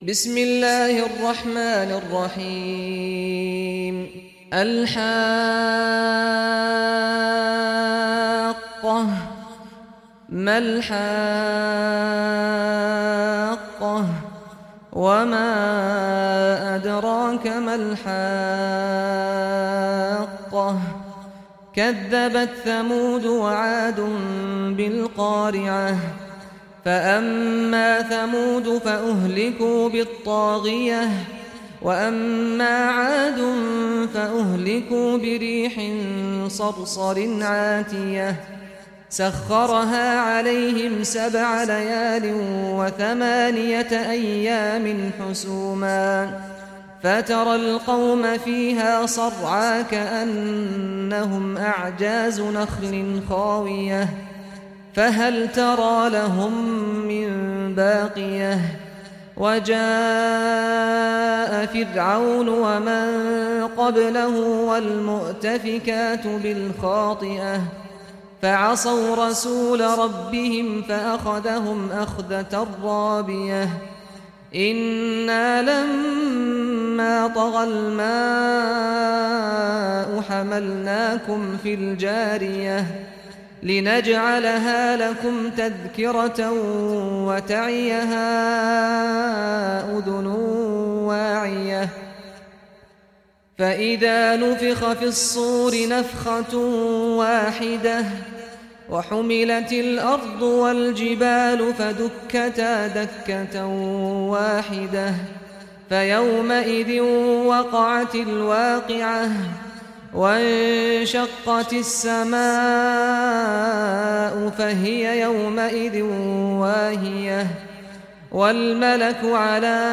Surah Al-Haqqah Recitation by Sheikh Saad Ghamdi
Surah Al-Haqqah, listen or play online mp3 tilawat / recitation in Arabic in the beautiful voice of Sheikh Saad al Ghamdi.